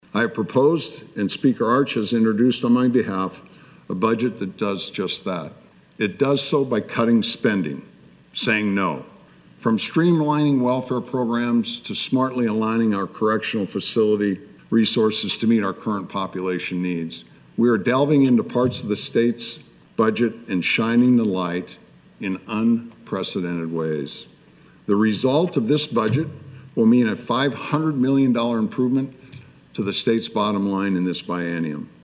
LOWERING STATE PROPERTY TAXES WAS ALSO A TALKING POINT, AND GOVERNOR PILLEN SAYS CUTTING STATE SPENDING WILL HELP BRING TAX RELIEF: